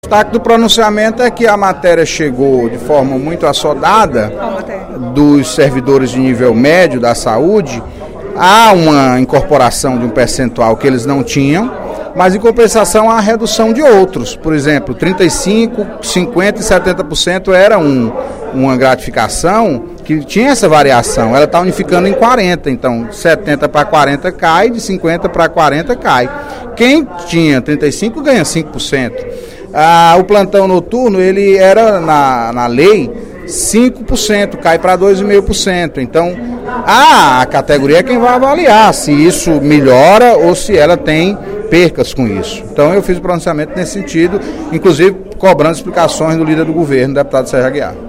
O deputado Antonio Carlos (PT) disse, durante o primeiro expediente da sessão plenária desta sexta-feira (28/12), que apesar de ser uma conquista dos servidores de nível médio da saúde a mensagem nº 7.455/12, de iniciativa do Governo do Estado, não contempla integralmente os trabalhadores.